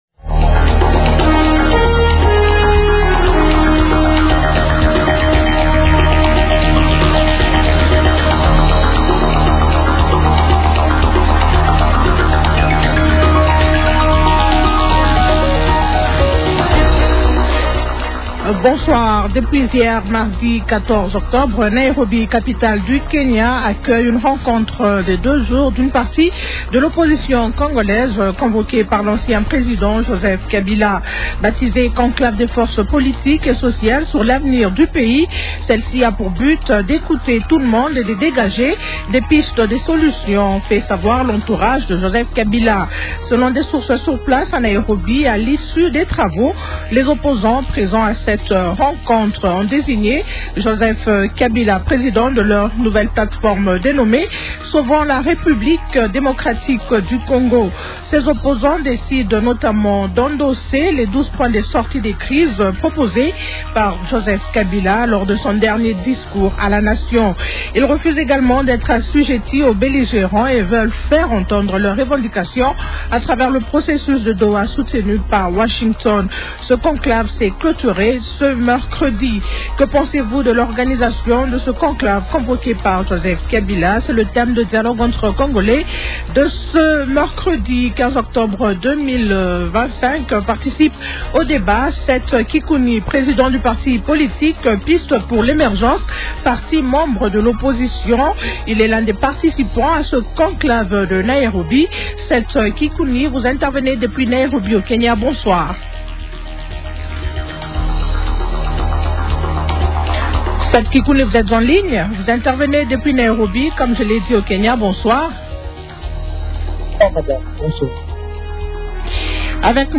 -Que pensez-vous de l’organisation du conclave convoqué par Joseph Kabila ? Invités :